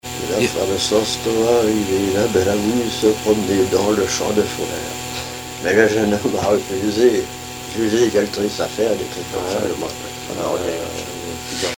Témoignage sur la pêche aux harengs et chansons
Pièce musicale inédite